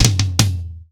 TOM     3C.wav